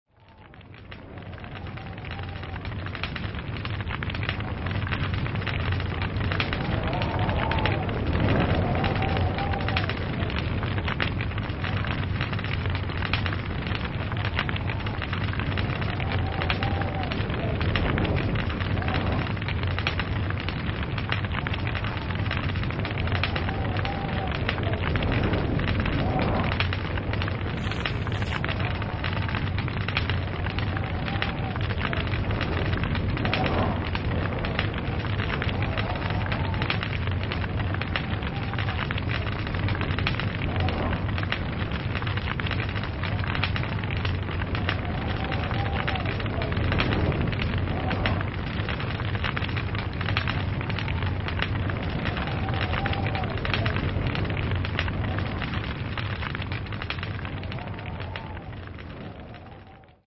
Log Cabin Fire
Category: Animals/Nature   Right: Personal